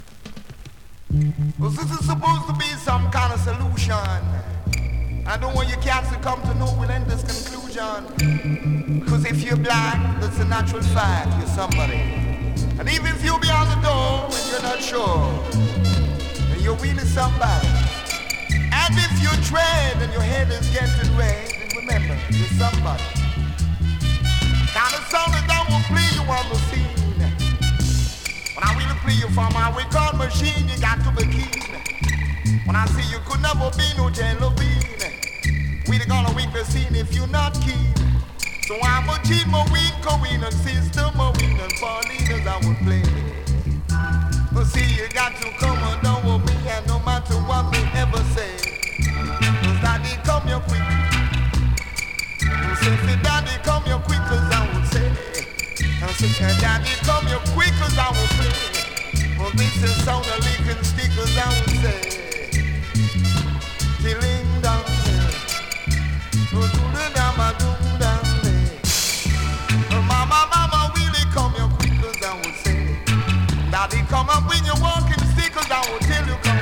NEW IN!SKA〜REGGAE
スリキズ、ノイズかなり少なめの